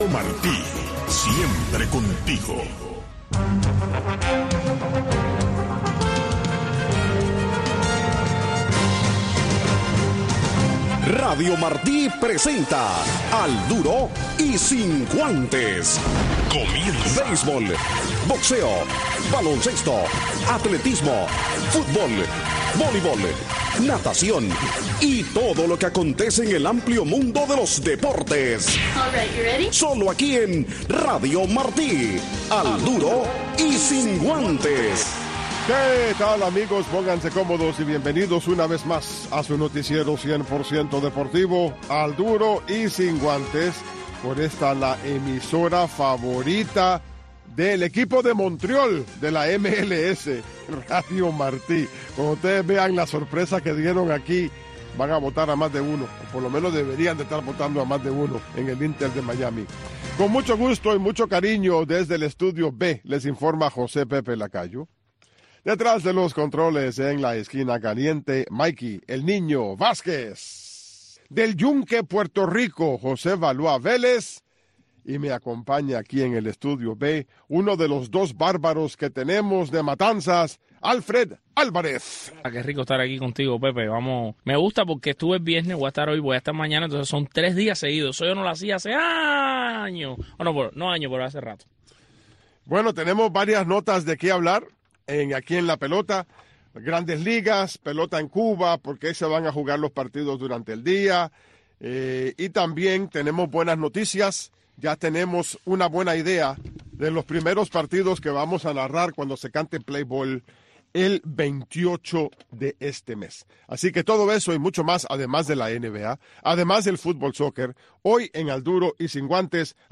Un resumen deportivo